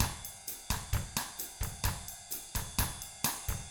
129BOSSAF3-L.wav